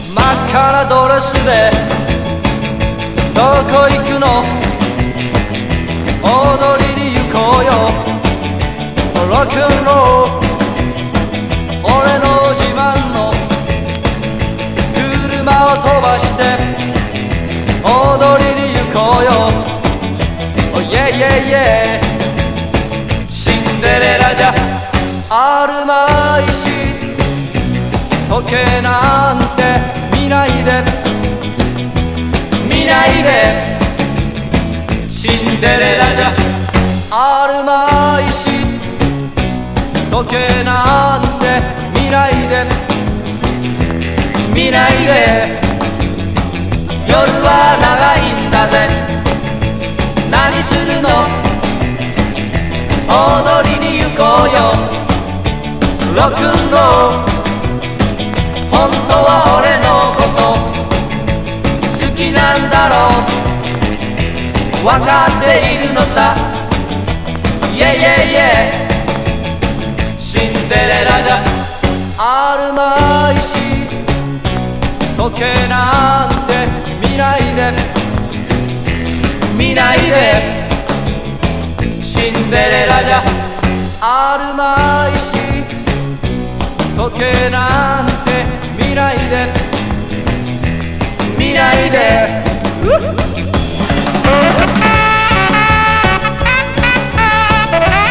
JAZZ CHORUS / JAPANESE CHORUS